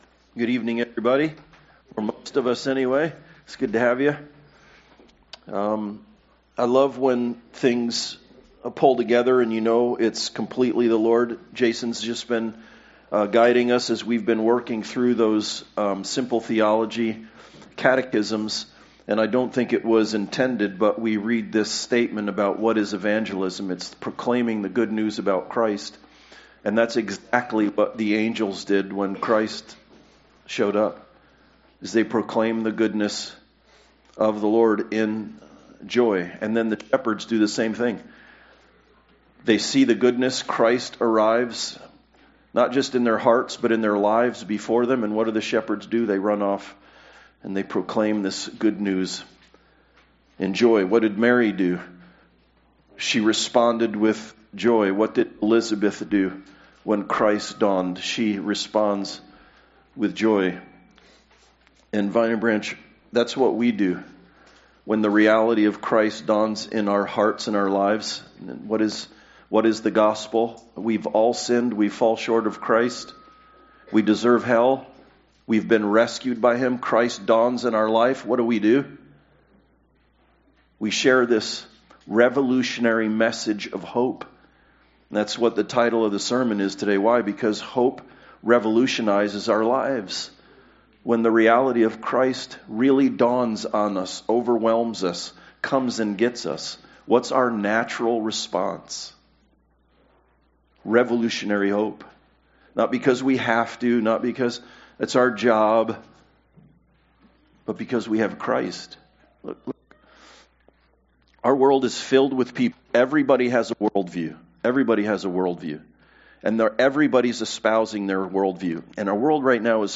Luke 2:1-14 Service Type: Sunday Service God works in the world in surprising ways to bring about revolutionary hope.